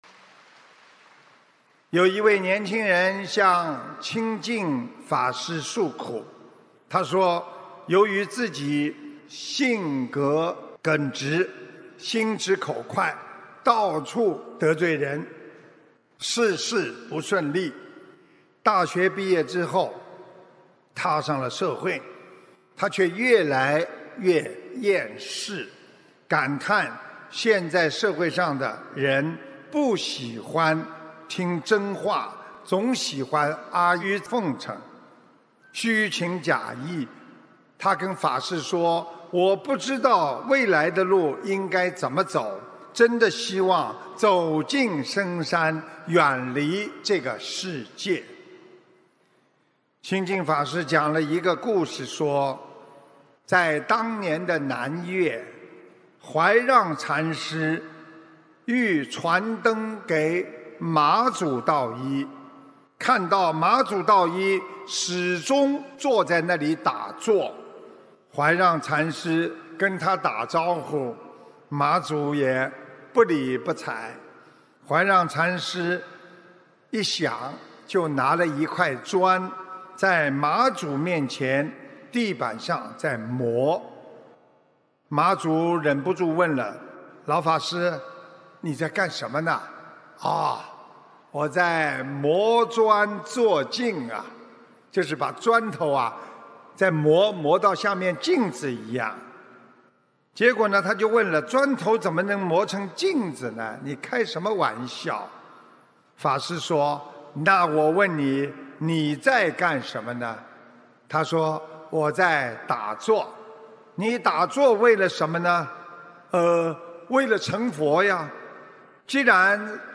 音频：一切从自身找原因！你才能破迷开悟！2019吉隆坡法会开示